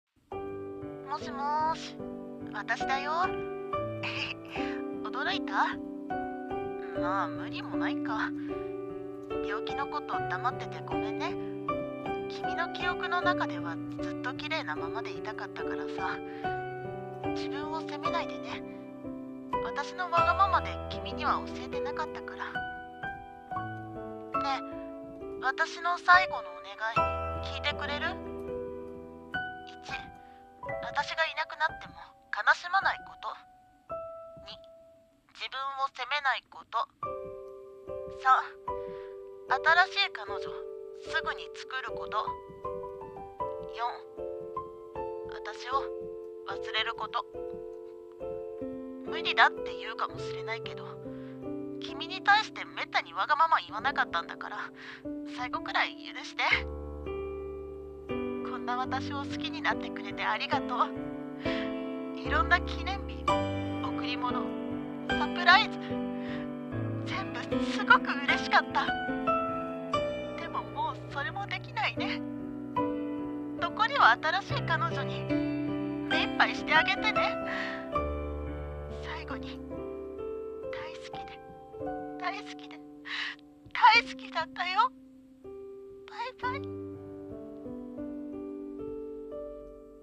【一人声劇】最後の願い【切ない台本】